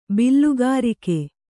♪ billugārike